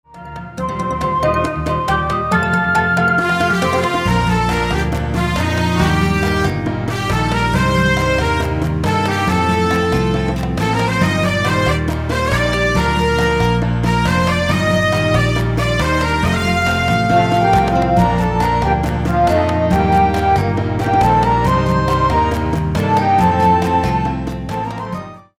過去に自分がリリースした曲のアレンジです。